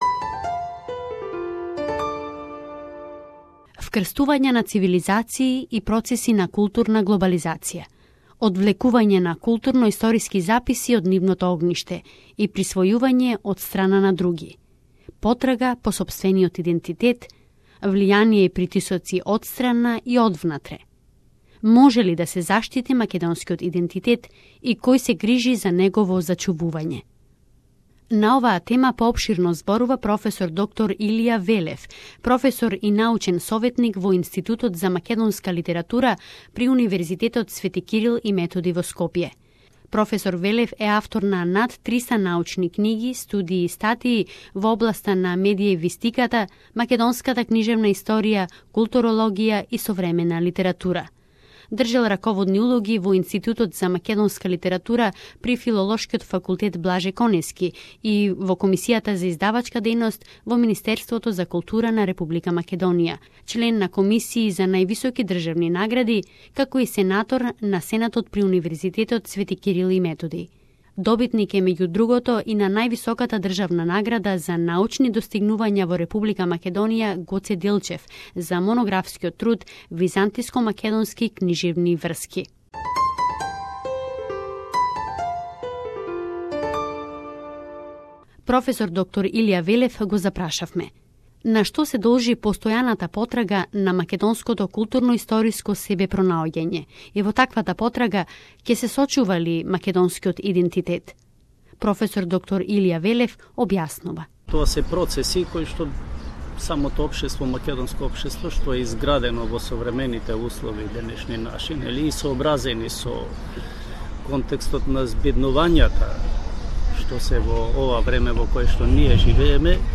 Може ли да се заштити македонскиот идентитет и на што се должи постојаната потрага по македонското културноисториско себепронаоѓање? Разговор